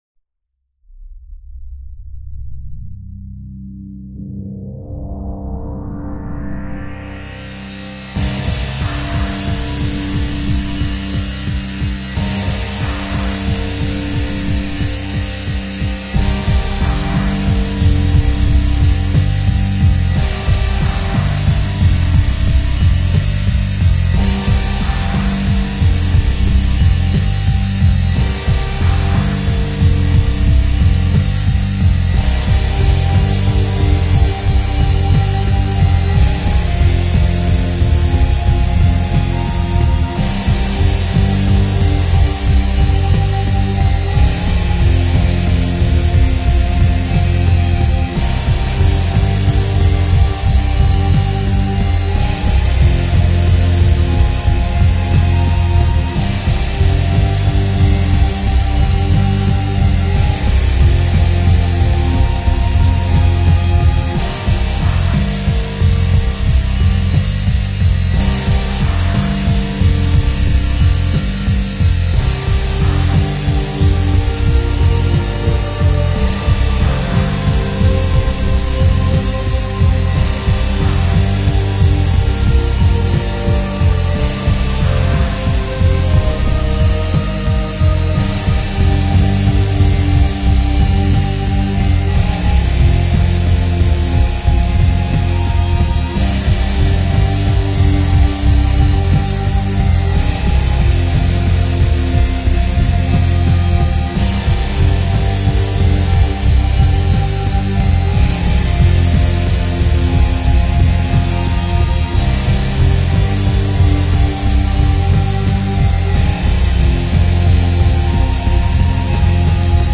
Melodic Rocker